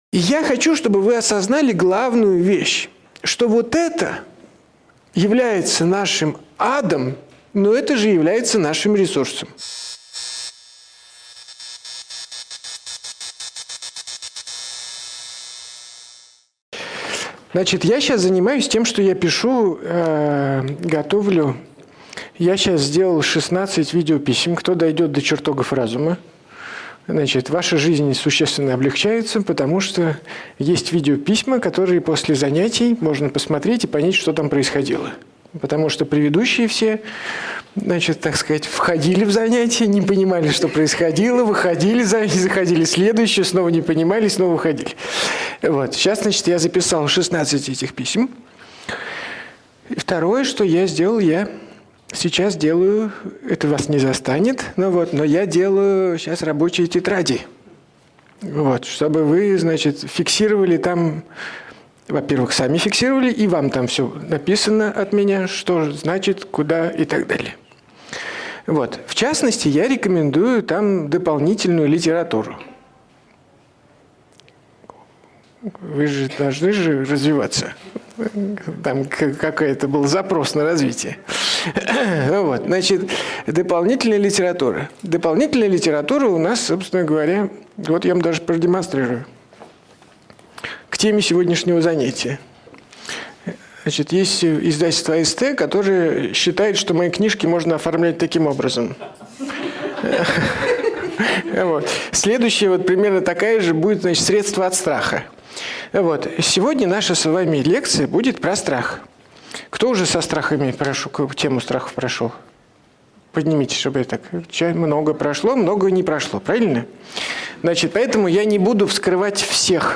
Aудиокнига Как использовать свои страхи? Автор Андрей Курпатов Читает аудиокнигу Андрей Курпатов.